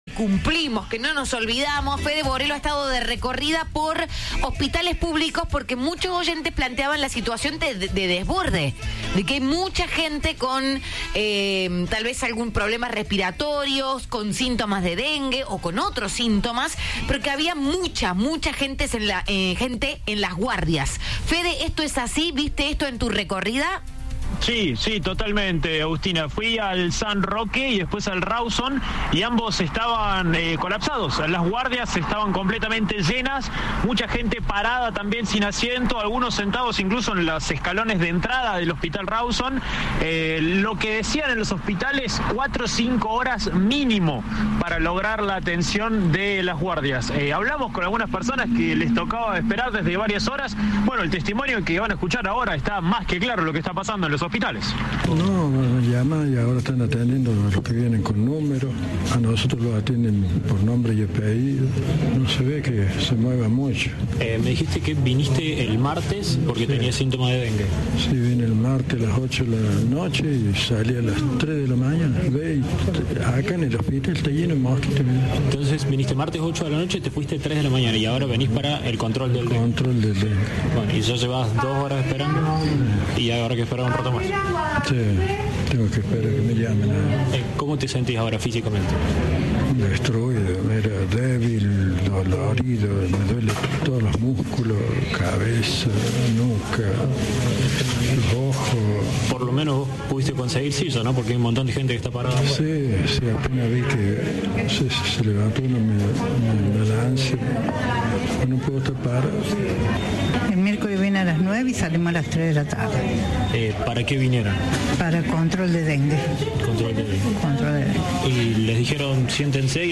Un hombre dijo a Cadena 3 que estaban atendiendo a los que se acercaban al Hospital Rawson por turno o por nombre y apellido.
En tanto, una mujer denunció que esperó seis horas para hacerse un control por síntomas compatibles con dengue.
Informe